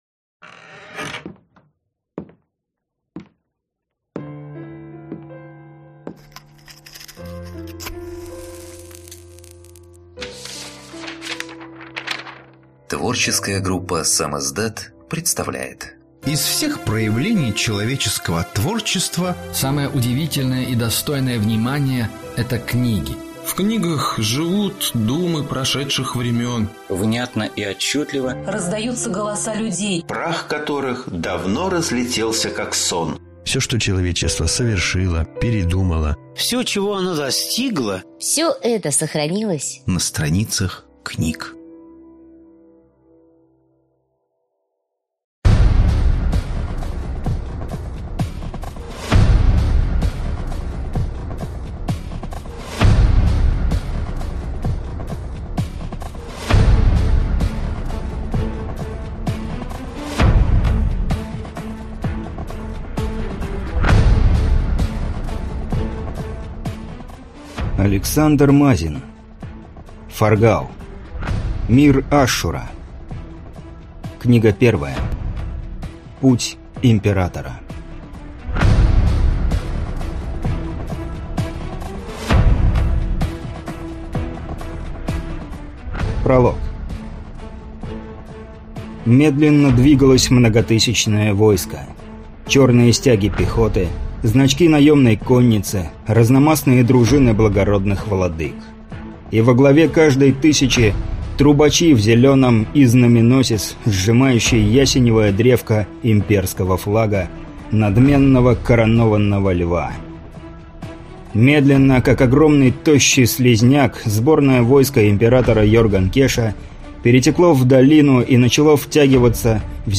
Аудиокнига Путь императора | Библиотека аудиокниг
Прослушать и бесплатно скачать фрагмент аудиокниги